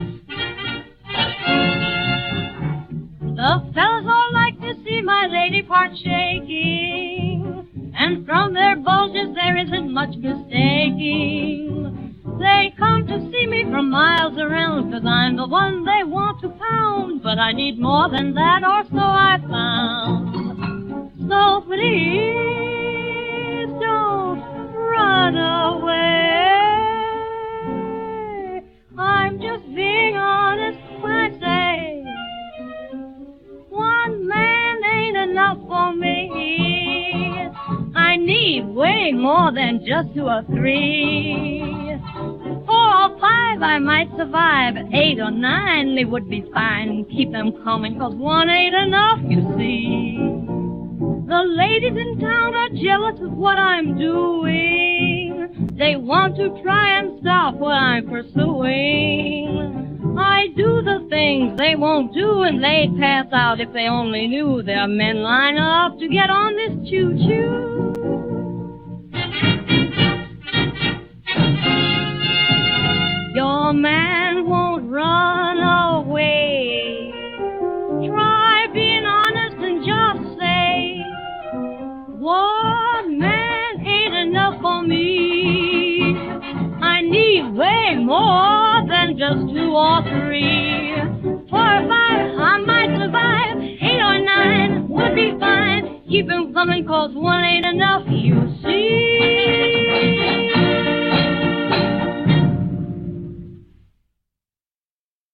One **** Ain't Enough for Me (1930s Big Band